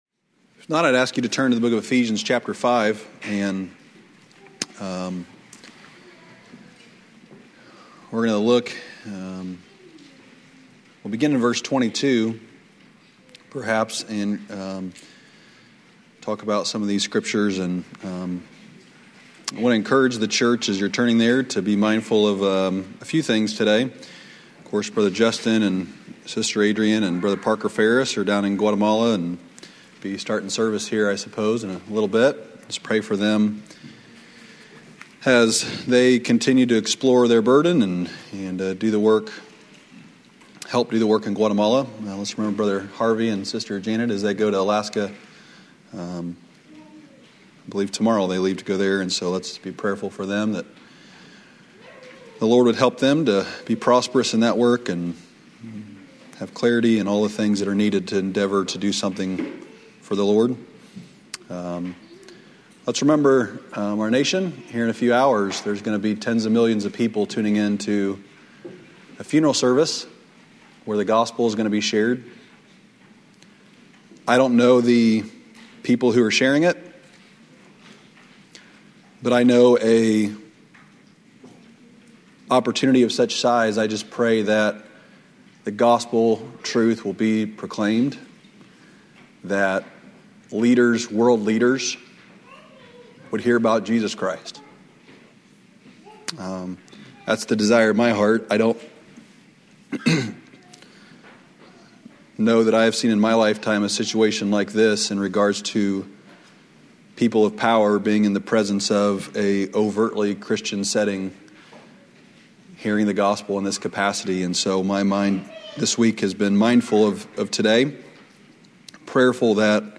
Lesson 1 from the 2009 Old Union Ministers School.